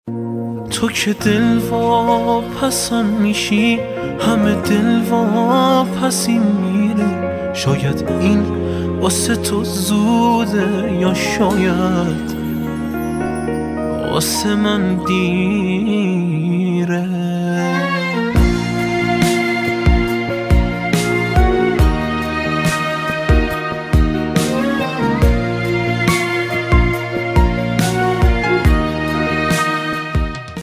رینگتون موبایل
(با کلام)